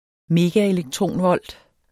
Udtale [ ˈmeːga- ]